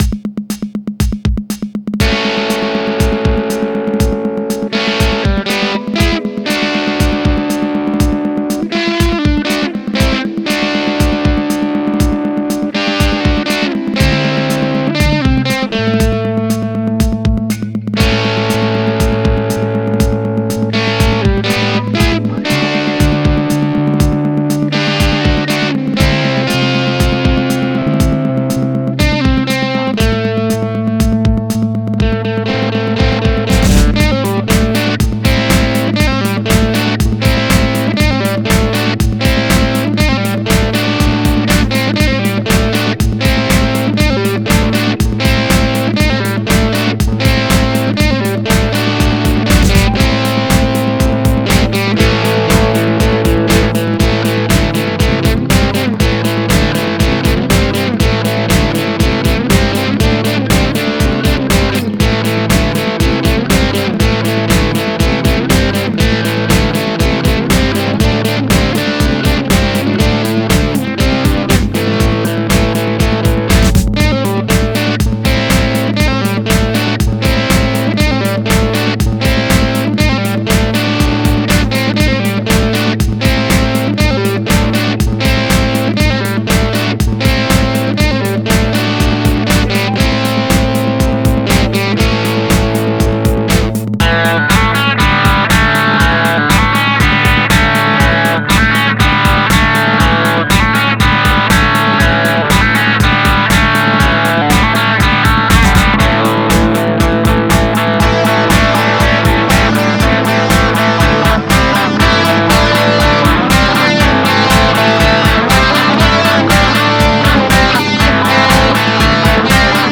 Post my crappy guitar music!
I claim copyright anyway, so there; 3. No, I cannot do anything about the general quality of the mix, as I am incompetent.